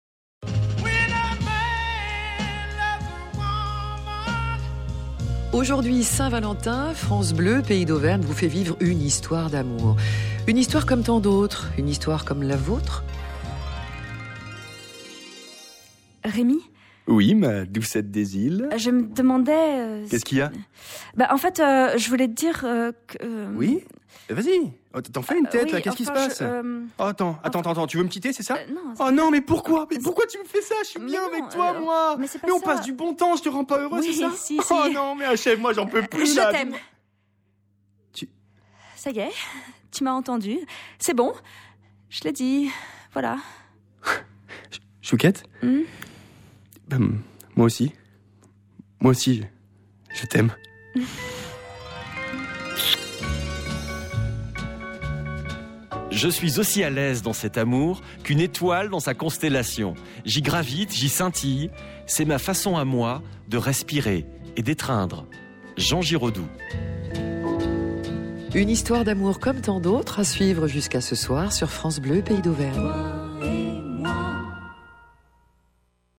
Personnages « chouchou/loulou » en dialogue, Voix masculine